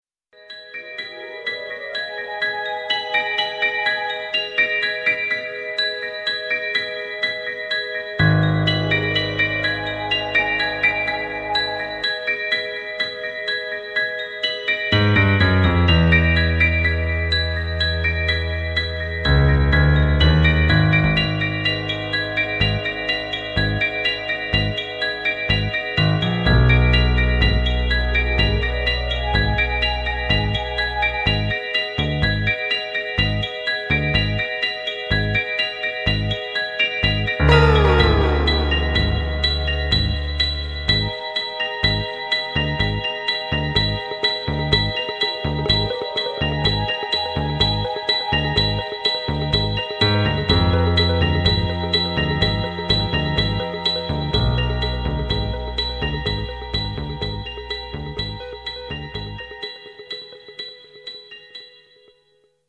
Latin Bblockbuster, end titles